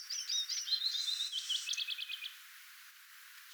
Erikoinen ääntely taustalla.
mika_laji_taustalla_erikoinen_aantely.mp3